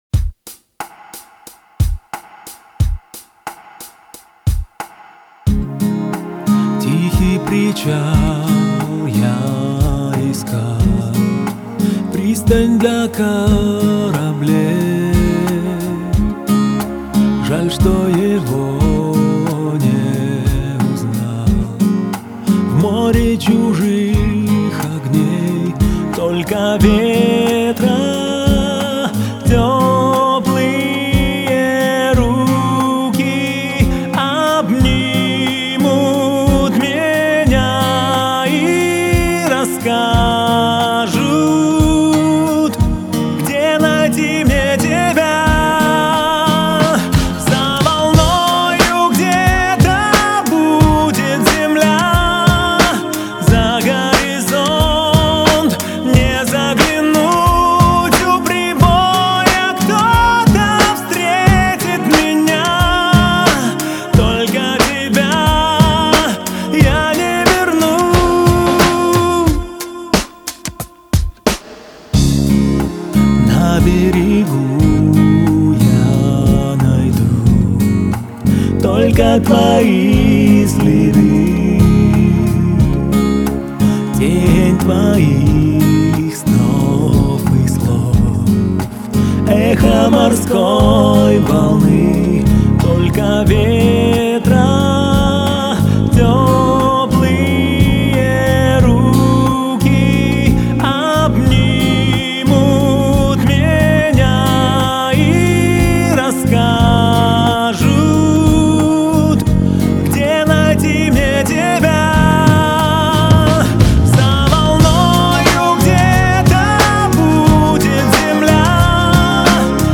это трек в жанре поп с элементами народной музыки